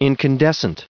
added pronounciation and merriam webster audio
1607_incandescent.ogg